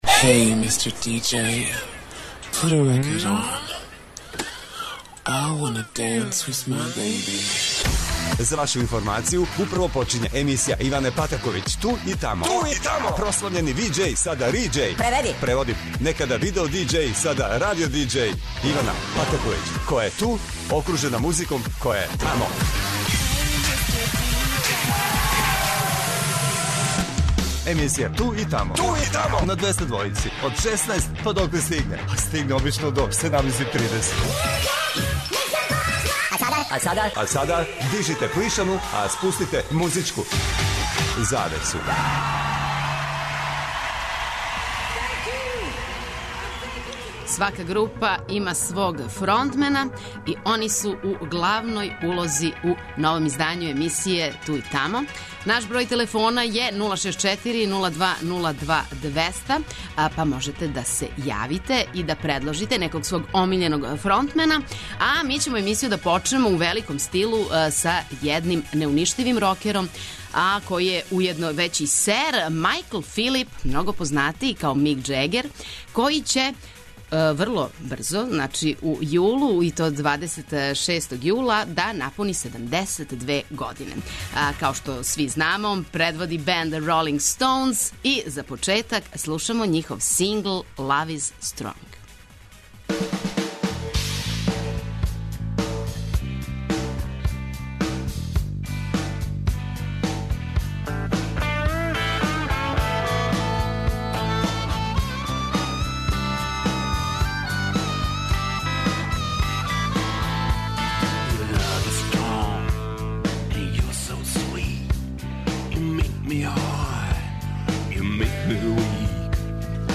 У емисији "Ту и тамо" ове суботе певачи рок бендова су у главној улози.
Очекују вас велики хитови, страни и домаћи, стари и нови, супер сарадње, песме из филмова, дуети и још много тога.